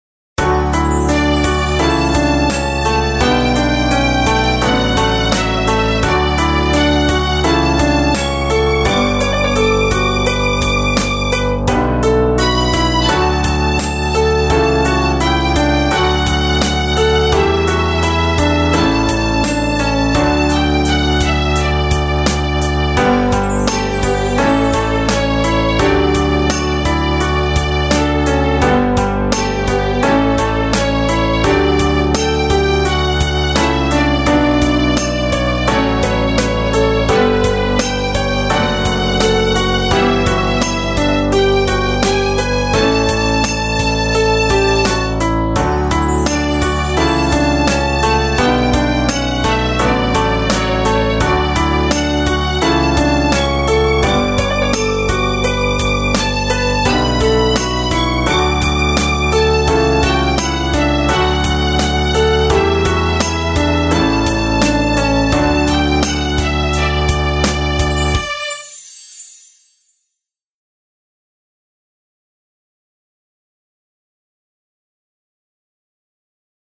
ショートスローテンポ